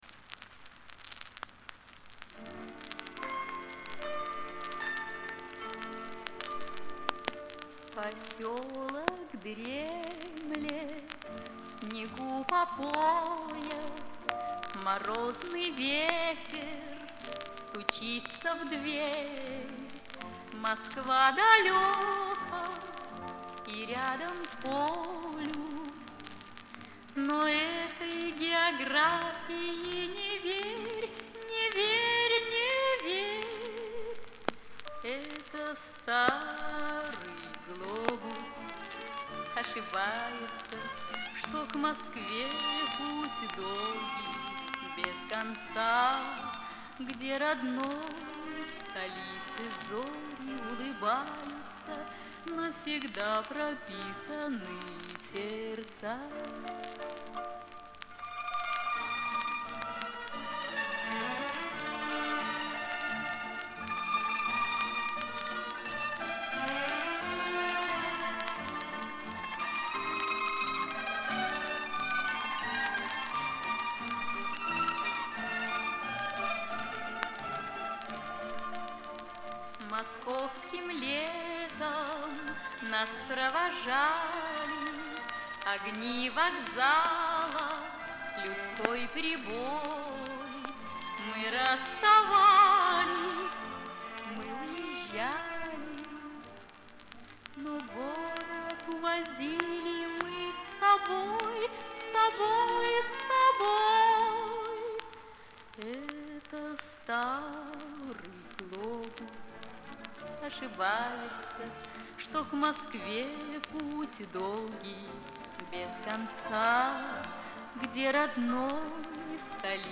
Милая, добродушная песенка